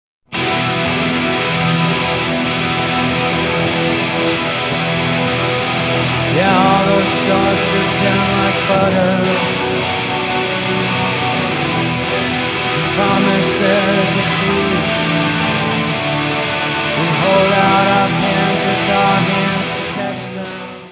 : stereo; 12 cm + folheto
Área:  Pop / Rock